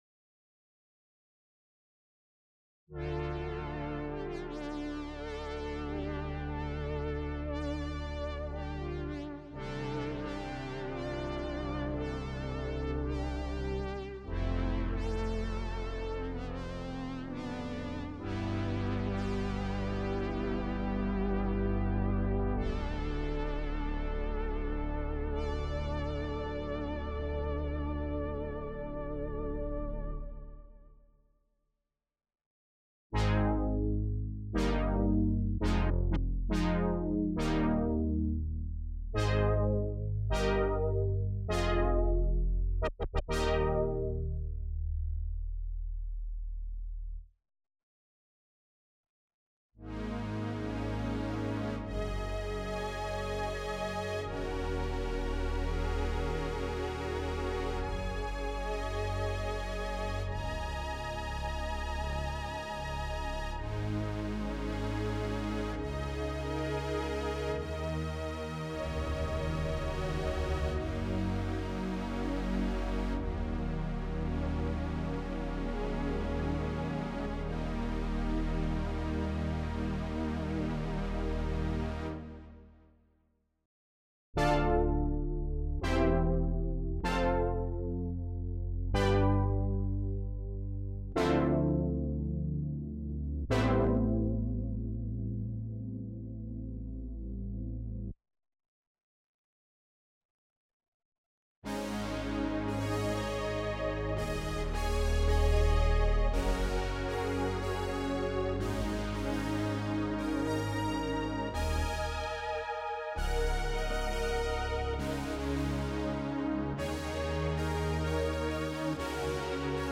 synthbrass-Radias.mp3